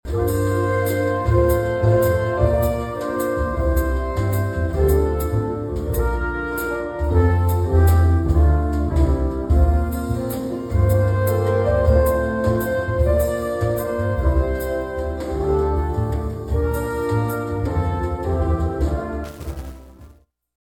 Sounds of the season performed at free concert inside Granada Theatre
Vocal and instrumental holiday songs, traditional and new, were played before an appreciative audience at the Emporia Granada Theatre on Thursday.
drums
on vocals — and featuring an all-star cast of jazz musicians.